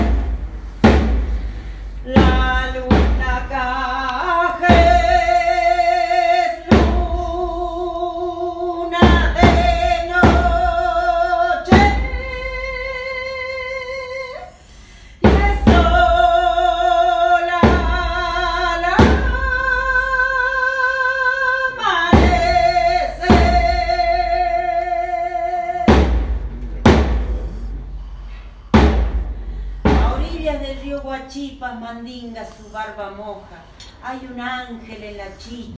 From then, on to Salta and sampling some nightlife in this city I fell in love with last time I was here:
We were then visited by an indigenous singer – in fact, she had been singing for the G20 summit in BA the previous week. Extraordinary shamanistic singing accompanied only by a single drum: